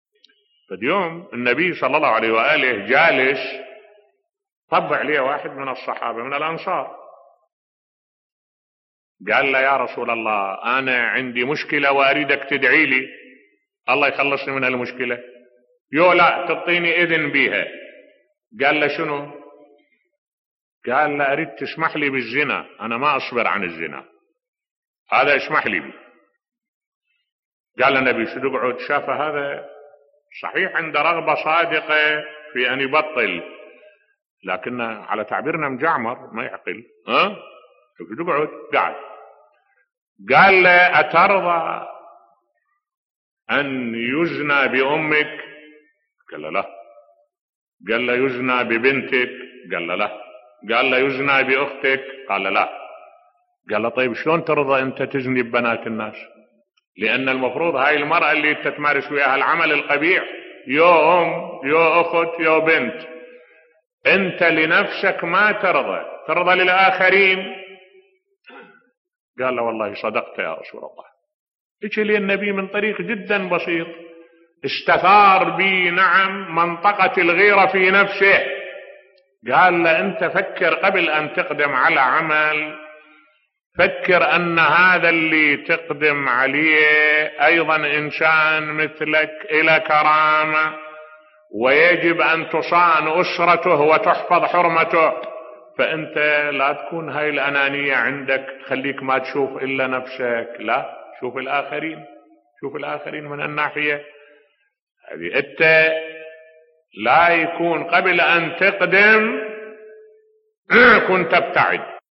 ملف صوتی صحابي يطلب من النبي أن يأذن له بالزنى بصوت الشيخ الدكتور أحمد الوائلي